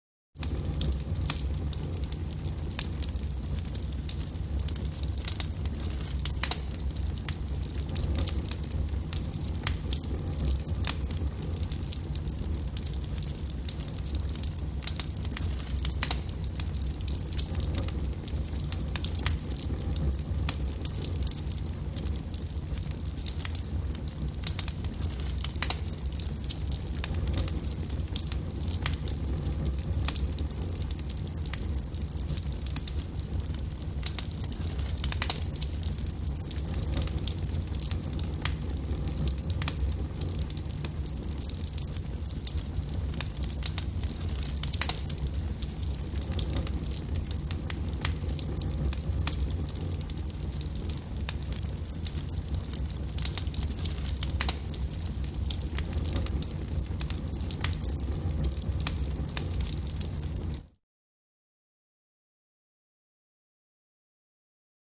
Wood Fire | Sneak On The Lot